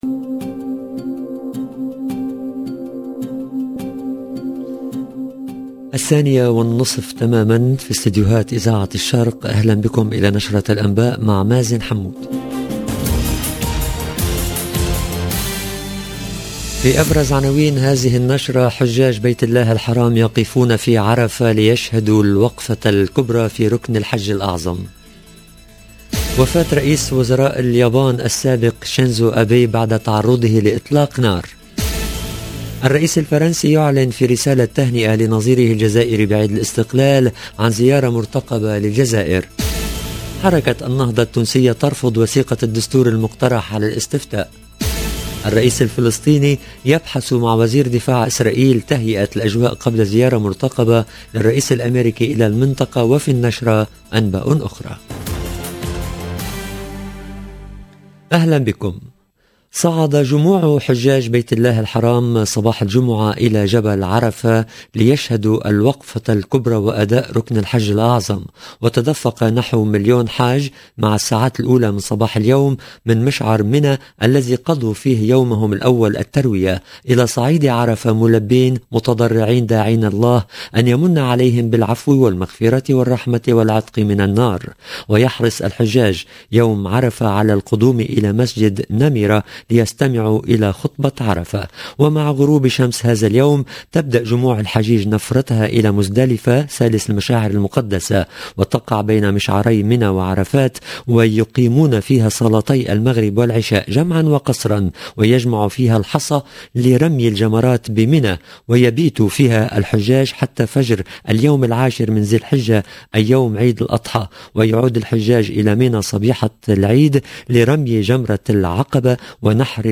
LE JOURNAL EN LANGUE ARABE DE LA MI-JOURNEE DU 8/07/22